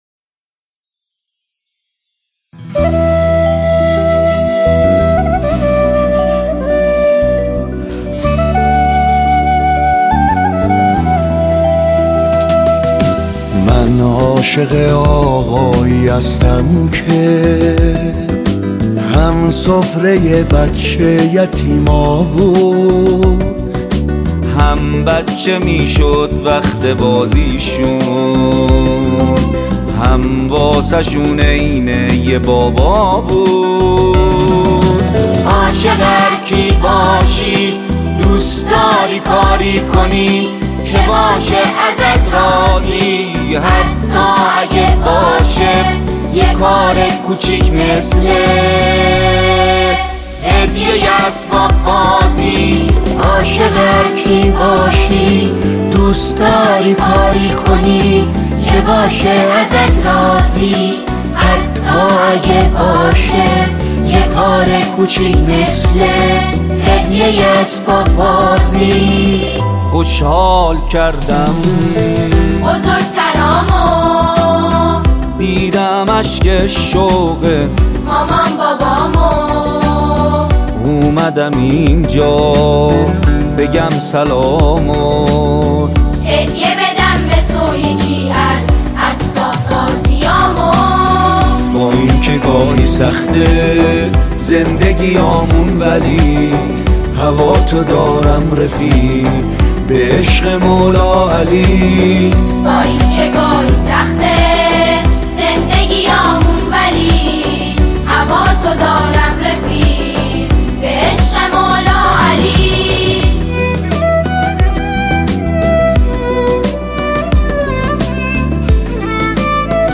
مذهبی